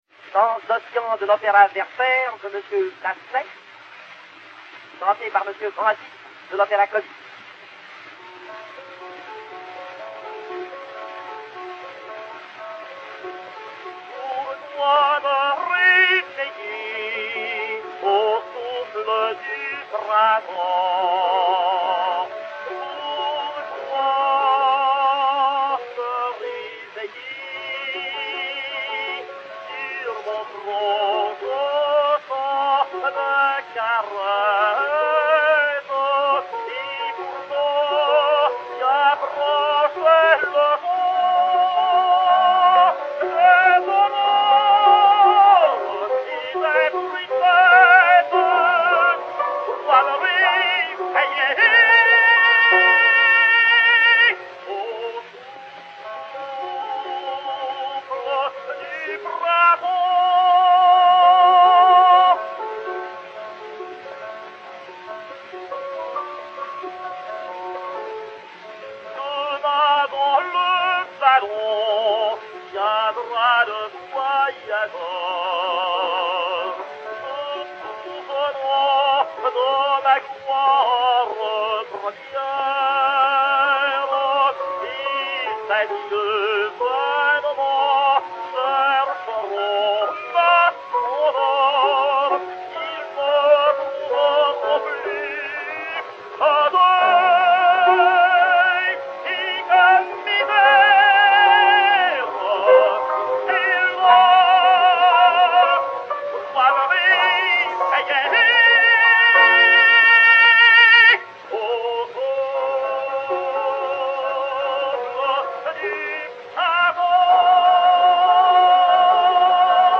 Ernest Van Dyck (Werther, créateur à Vienne) et Piano
Pathé mat. 60604, enr. à Londres en 1903